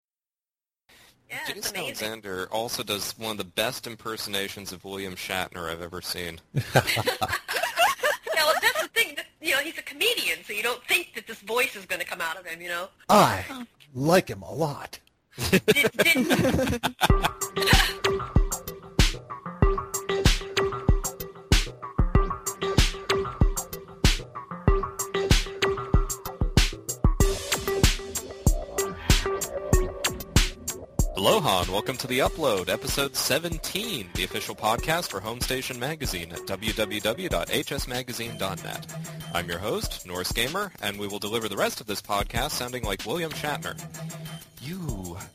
The Upload is back, and this time there’s some full-tilt ranting going on!
This particular episode is packed with discussion: